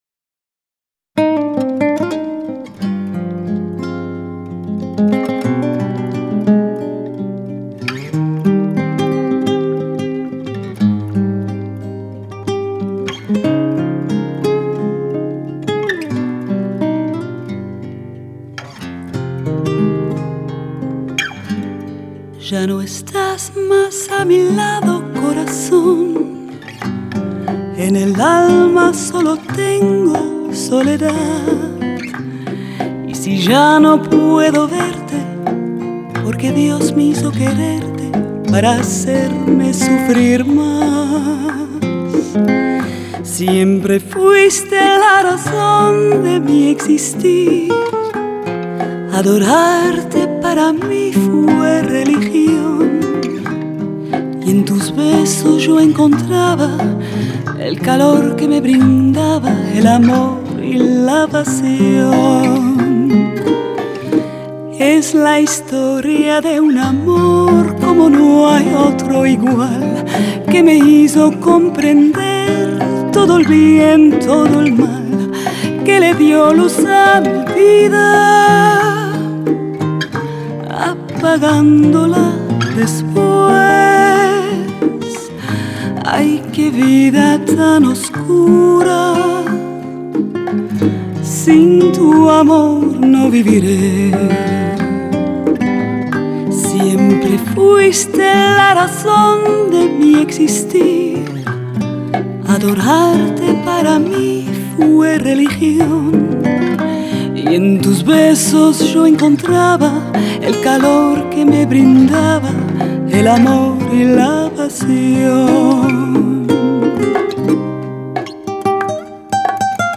模糊、恬美，一个个难以忘怀的画面随温热恍惚的歌声一一定格，
录音效果非常的发烧。听听那压缩成MP3后还保留着清晰的吉他擦弦声，
丝质般细致的磁性音质深具性感魅力，
演绎歌曲时风格温和优雅而少有花哨做作。